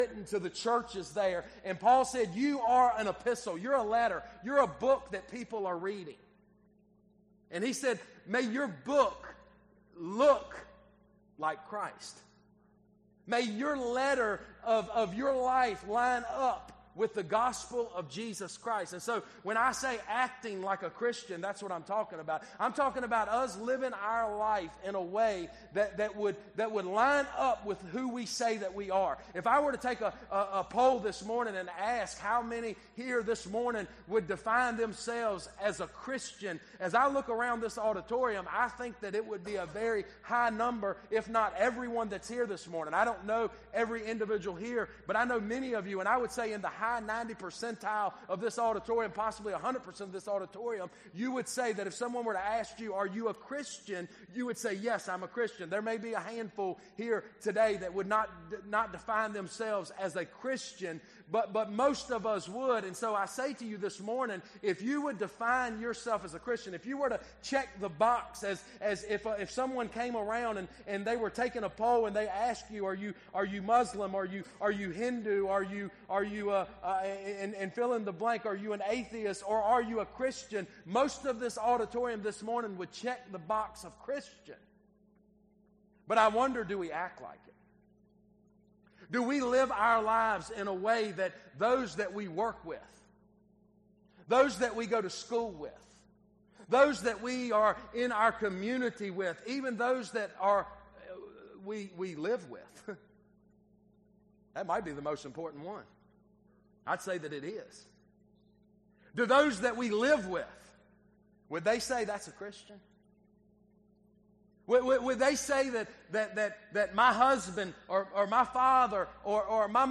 Sermons Archive • Page 10 of 167 • Fellowship Baptist Church - Madison, Virginia